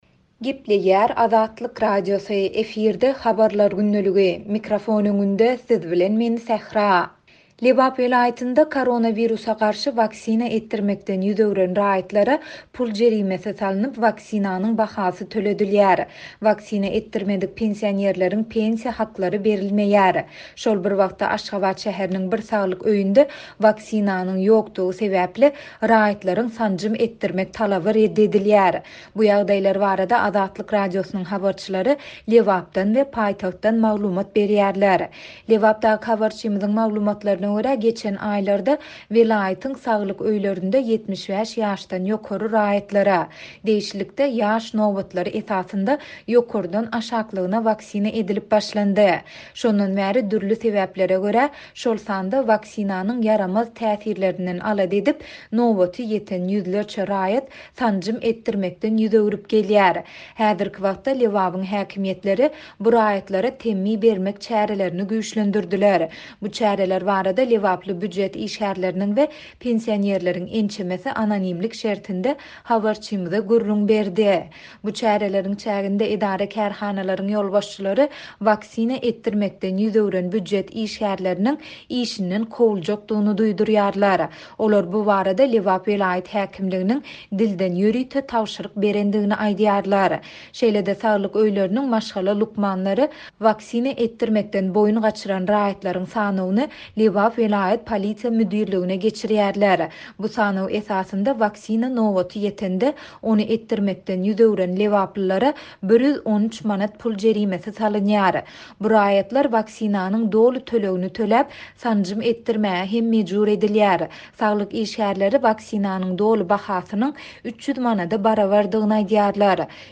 Bu ýagdaýlar barada Azatlyk Radiosynyň habarçylary Lebapdan we paýtagtdan maglumat berýärler.